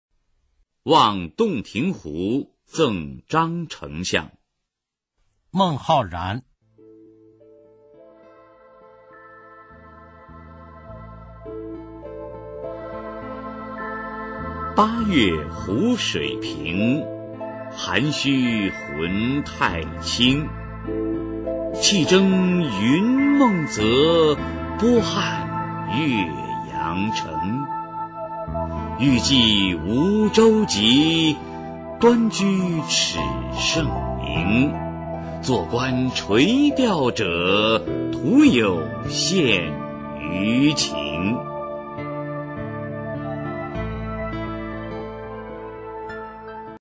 [隋唐诗词诵读]孟浩然-望洞庭湖赠张丞相 配乐诗朗诵